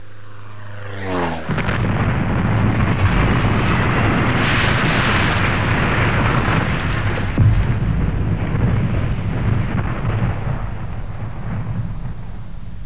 دانلود آهنگ طیاره 13 از افکت صوتی حمل و نقل
جلوه های صوتی
دانلود صدای طیاره 13 از ساعد نیوز با لینک مستقیم و کیفیت بالا